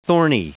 Prononciation du mot thorny en anglais (fichier audio)